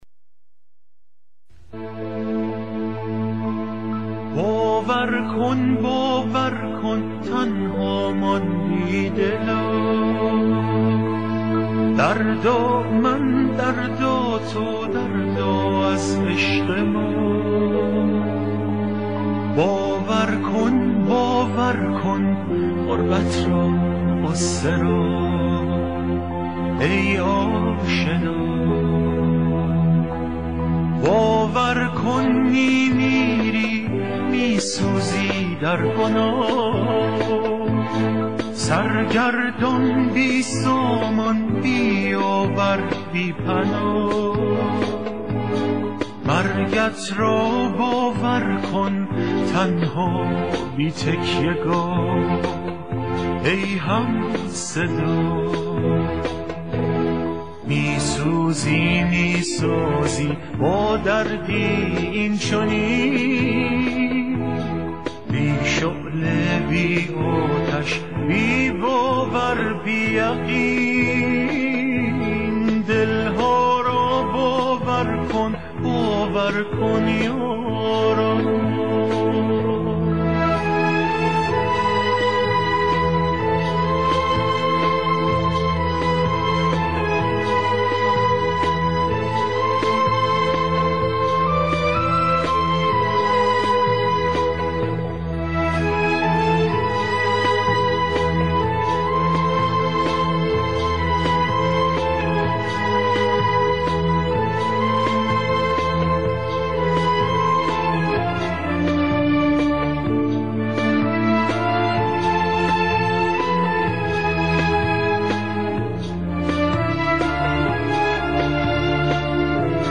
تیتراژ